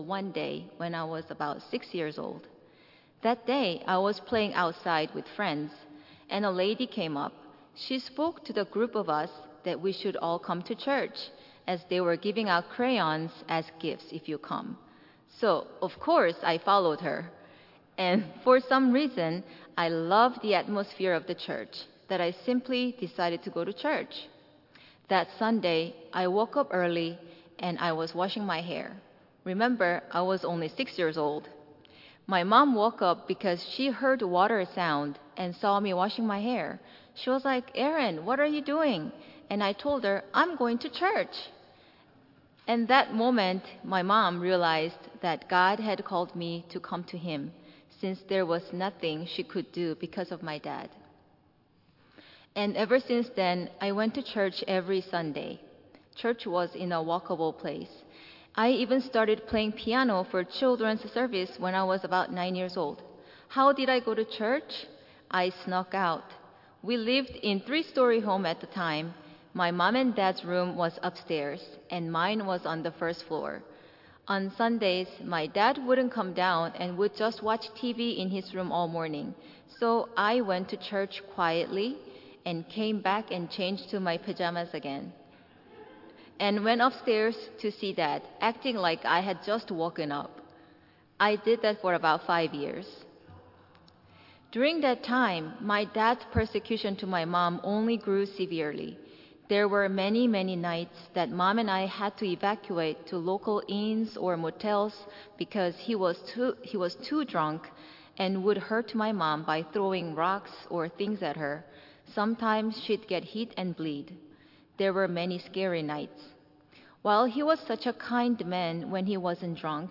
Tagged with Michigan , Sermon , testimony , Waterford Central United Methodist Church , witness , Worship Audio (MP3) 10 MB Previous It's Not About Us - Guess Who It Is About!